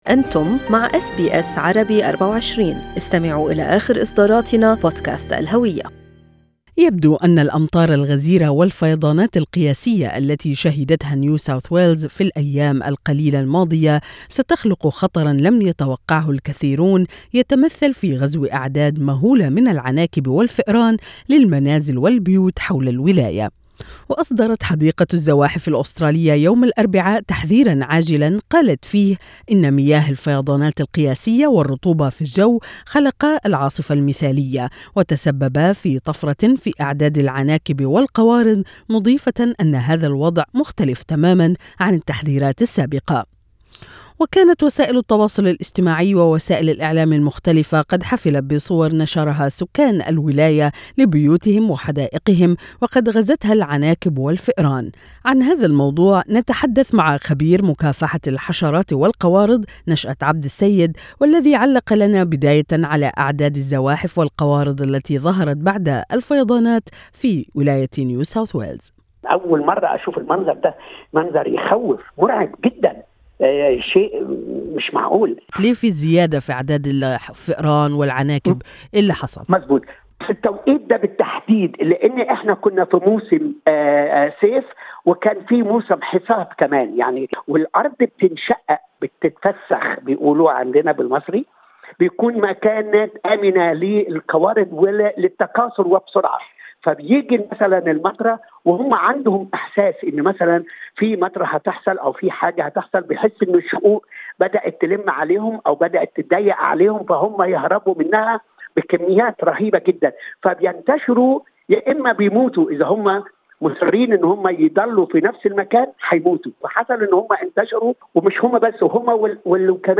استمعوا الى اللقاء الكامل مع خبير مكافحة الحشرات والقوارض